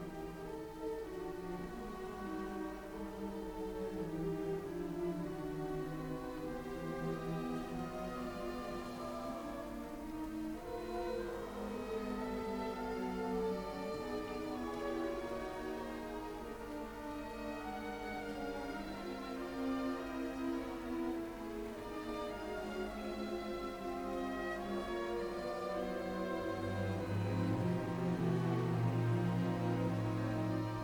0 => "Musique orchestrale"